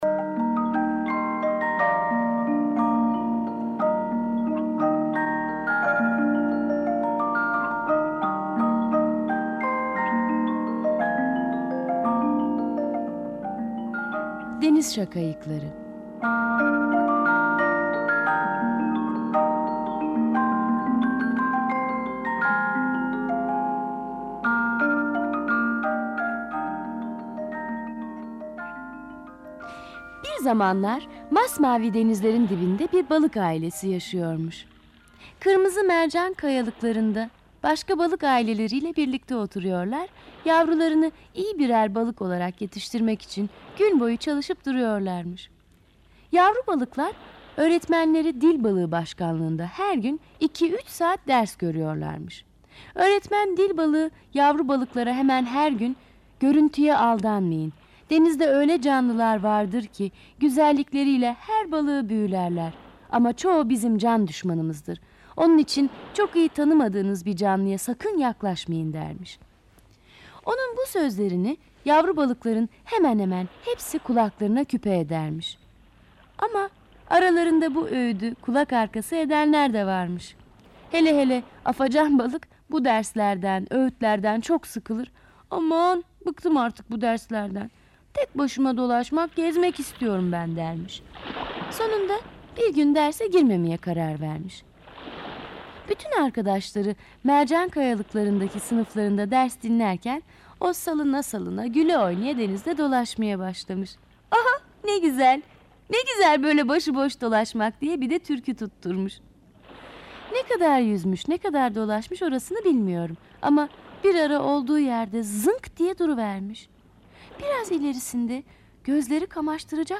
Deniz şakayıkları sesli masalı, mp3 dinle indir
Sesli Çocuk Masalları